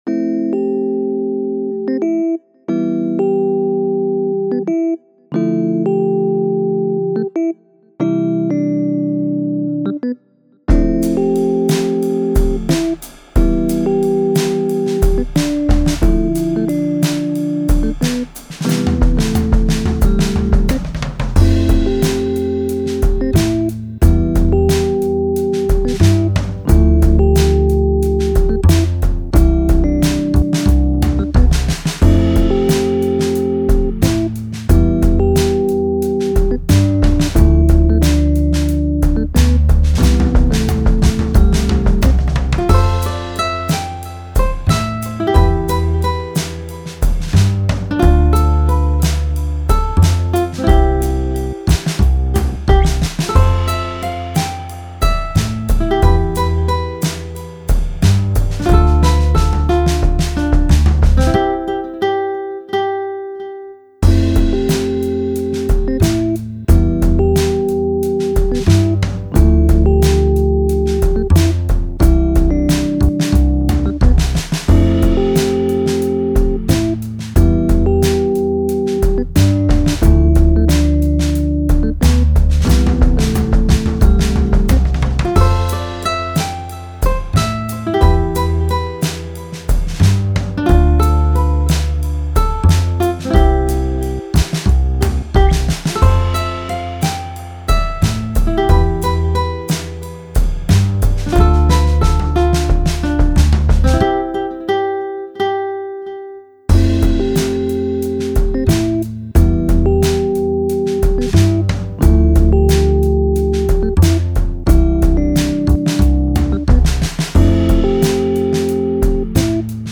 Beats to jam with...